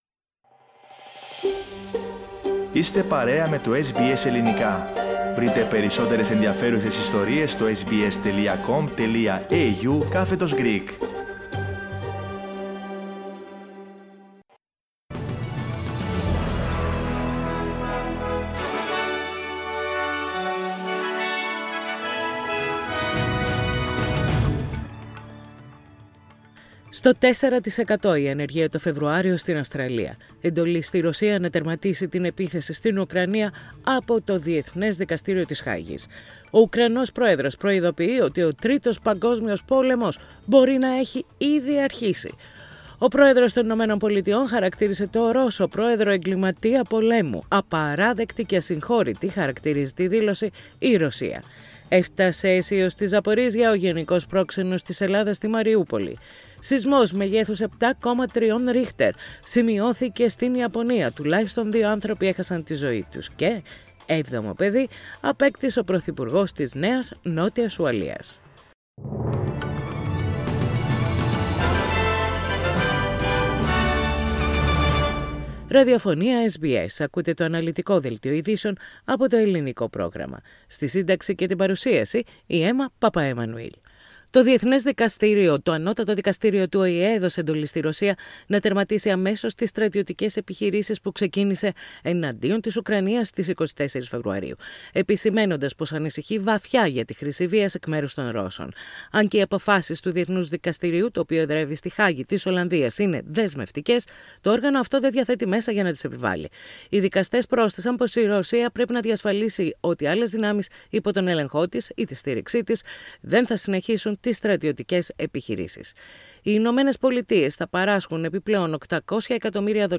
Δελτίο ειδήσεων - Πέμπτη 17.3.22
News in Greek. Source: SBS Radio